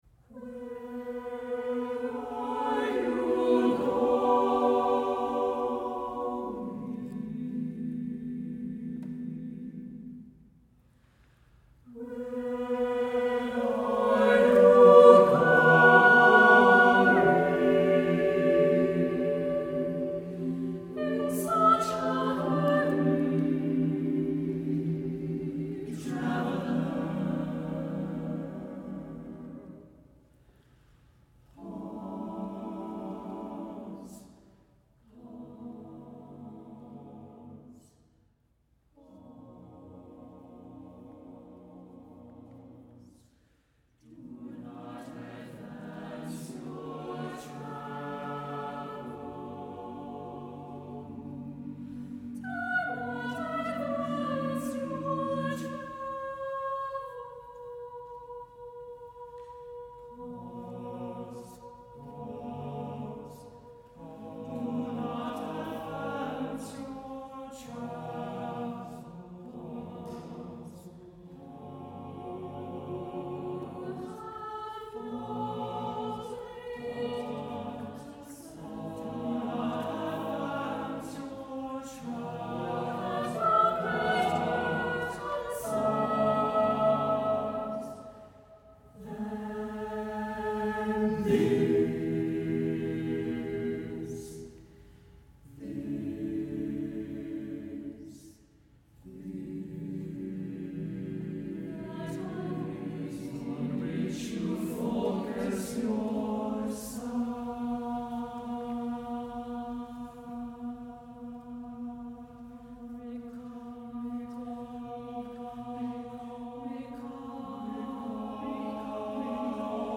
for SATB choir a cappella
Phrases are extended with measures of 5/4 time.